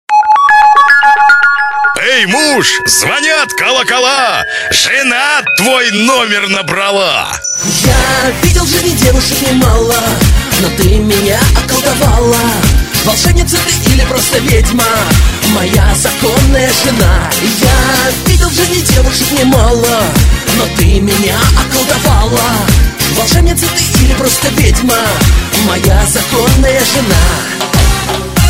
• Качество: 320, Stereo
поп
мужской голос
громкие
веселые